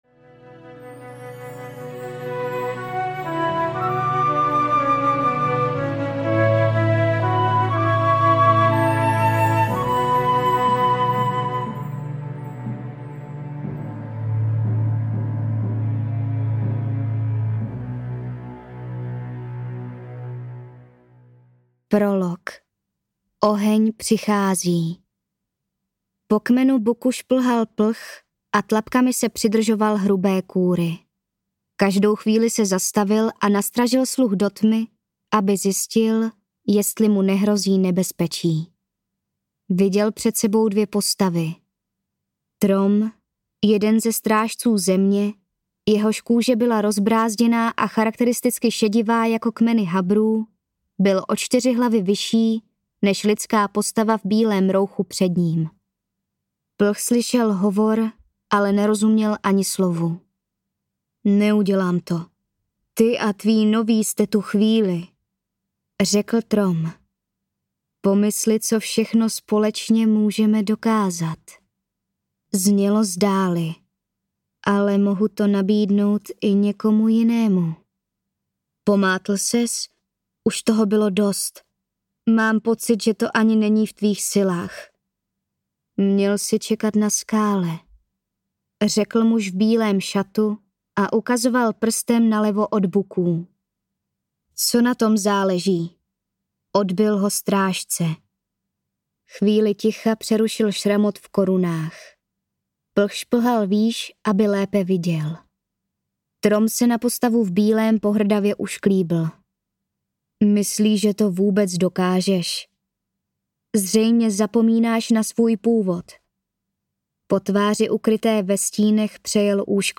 Strážci země audiokniha
Ukázka z knihy
strazci-zeme-audiokniha